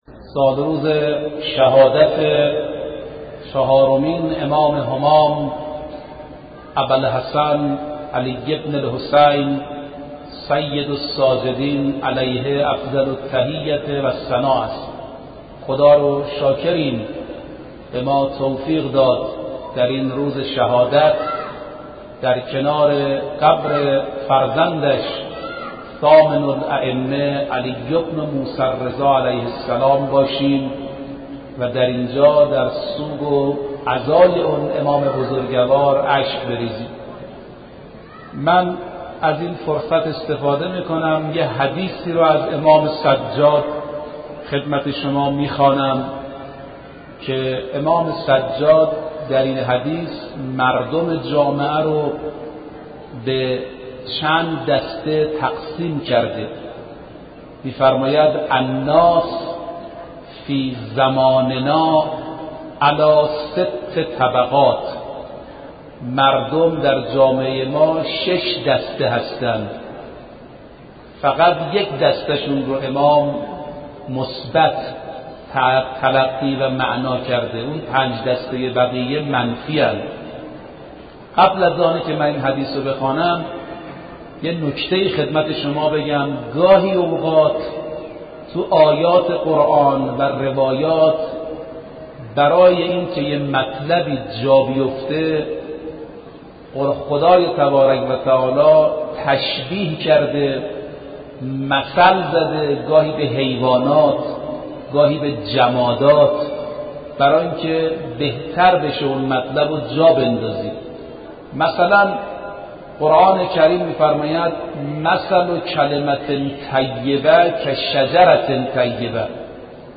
سخنرانی شهادت امام سجاد